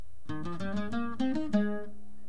para bajosexto!!!!!